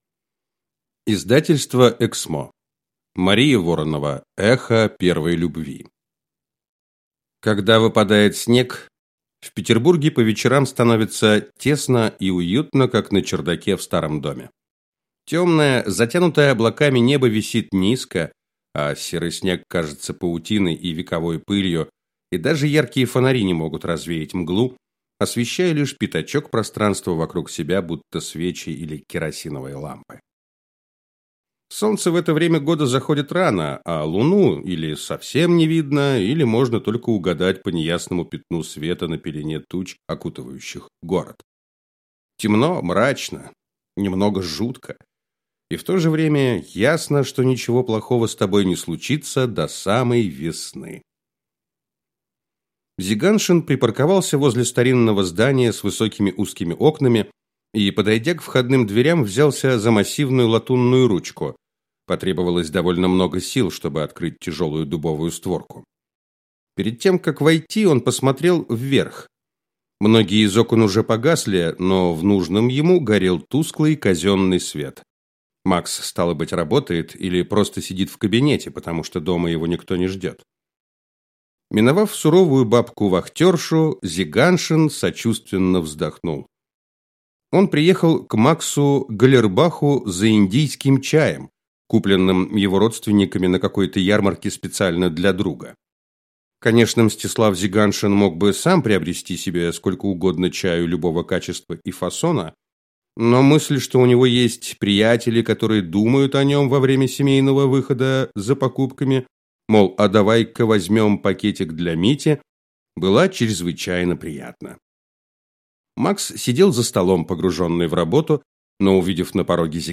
Аудиокнига Эхо первой любви | Библиотека аудиокниг